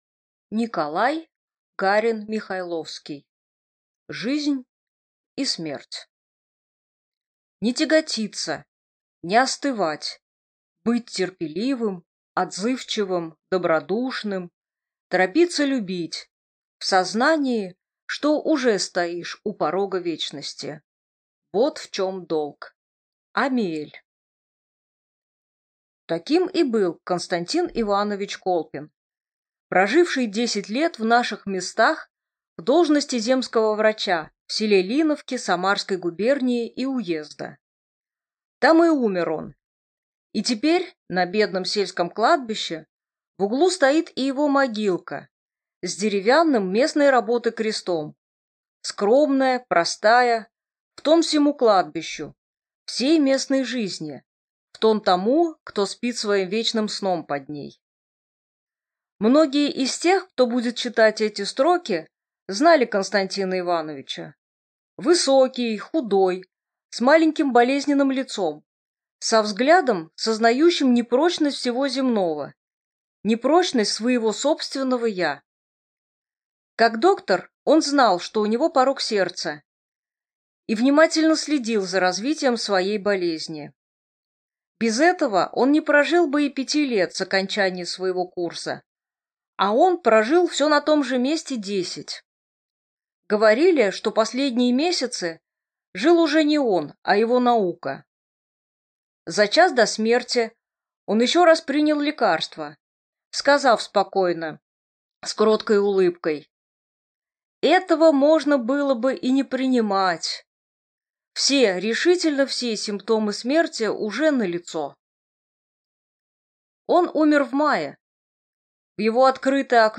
Аудиокнига Жизнь и смерть | Библиотека аудиокниг